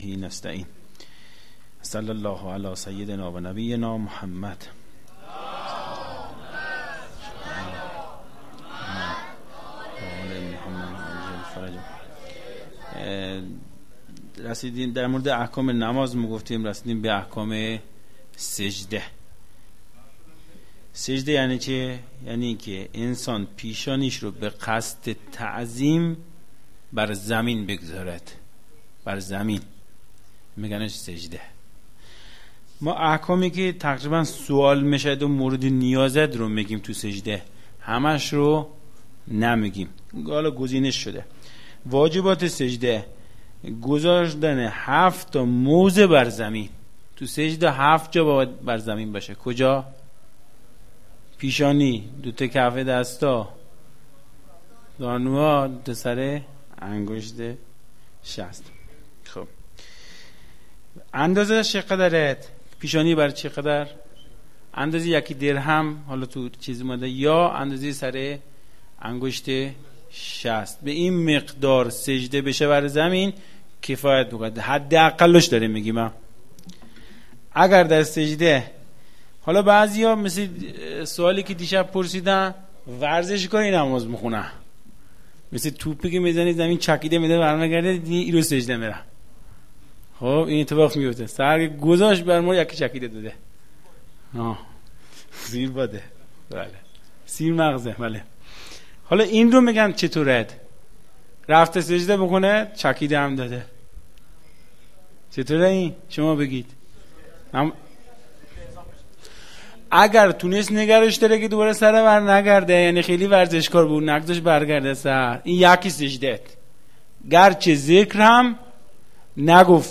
بیان احکام